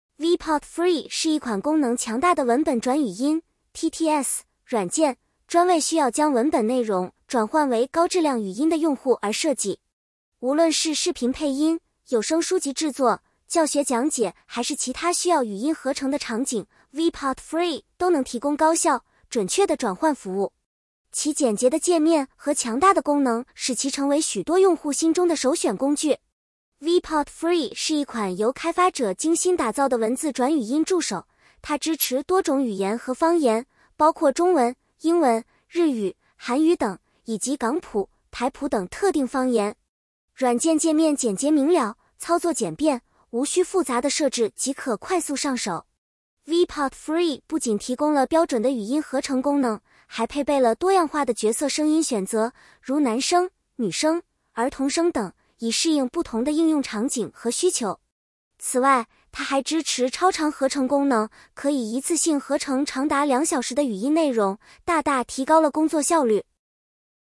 这里给大家贴一段转语音的效果试听一下：